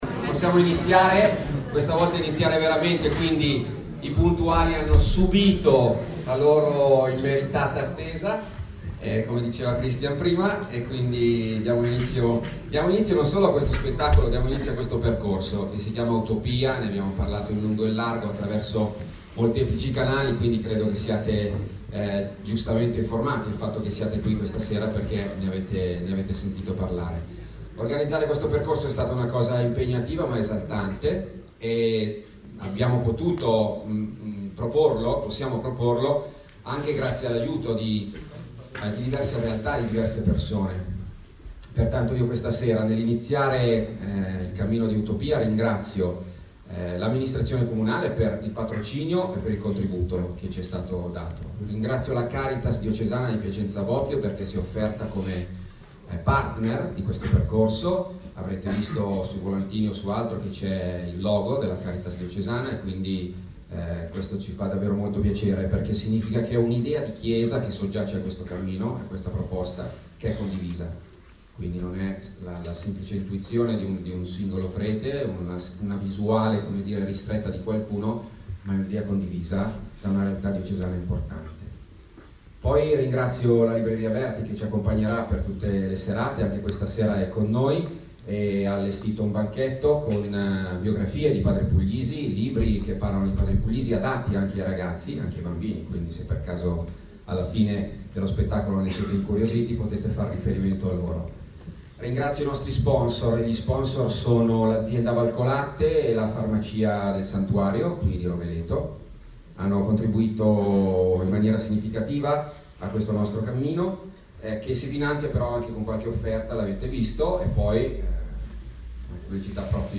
Introduzione